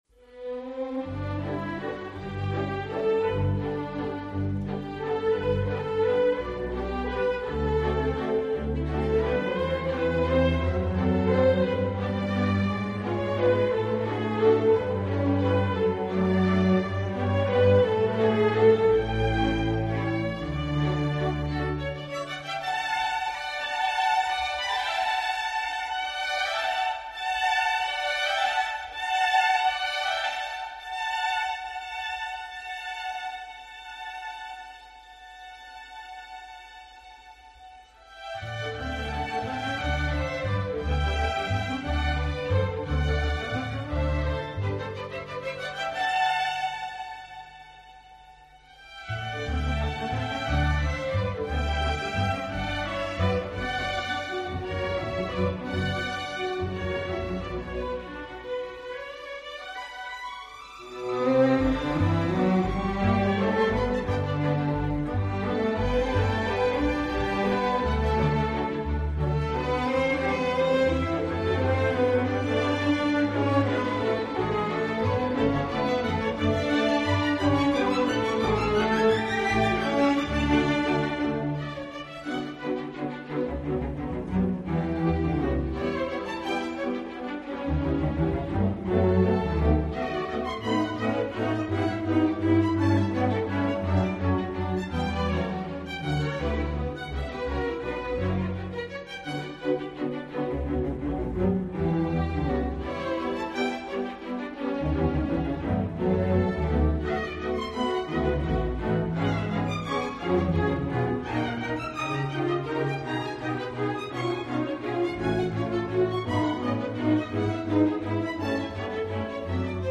圆舞曲Waltz